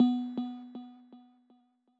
scoge-menu1-move.wav